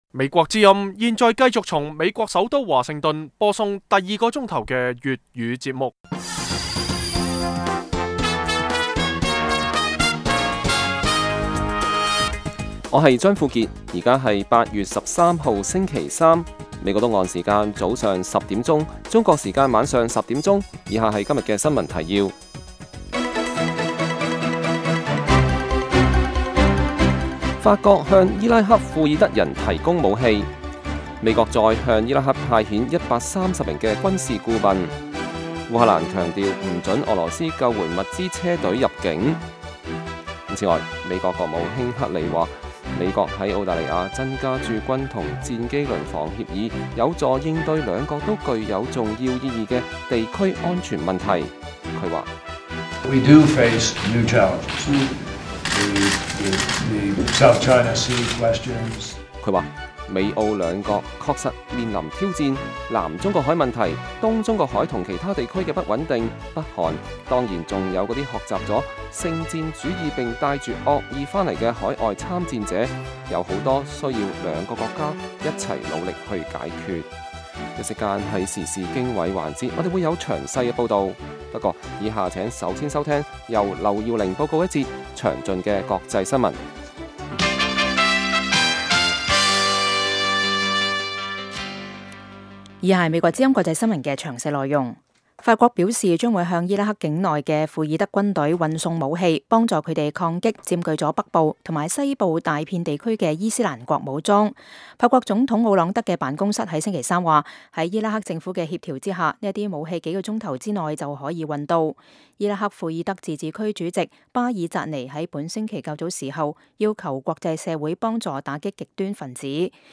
每晚 10點至11點 (1300-1400 UTC)粵語廣播，內容包括簡要新聞、記者報導和簡短專題。